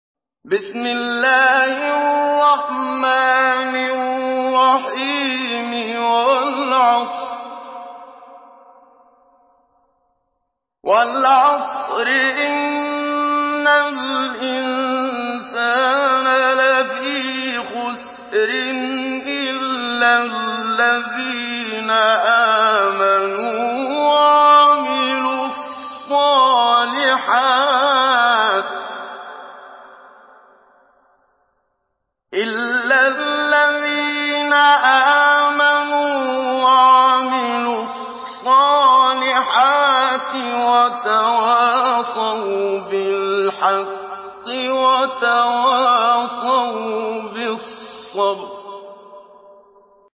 Surah Al-Asr MP3 in the Voice of Muhammad Siddiq Minshawi Mujawwad in Hafs Narration